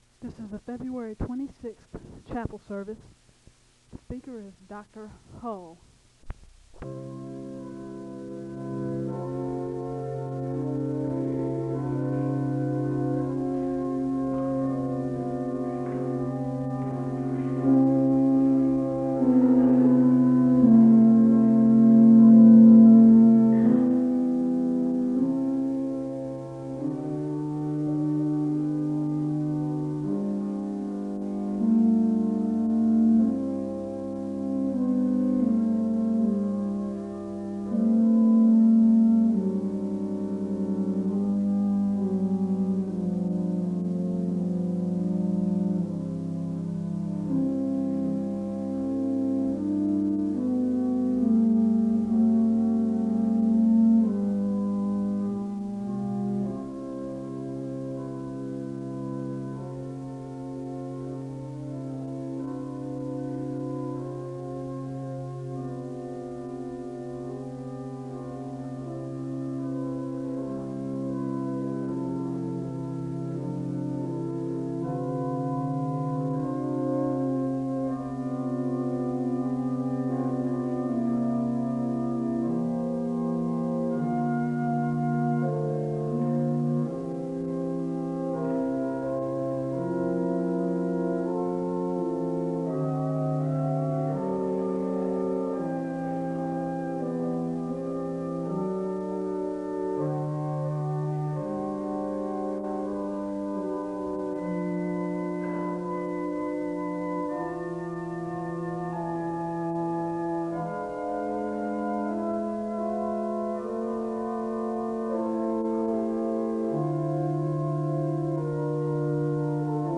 SEBTS Chapel
The service begins with organ music and a word of prayer (0:00-7:48).
The choir sings a song of worship (9:48-12:06).
Location Wake Forest (N.C.)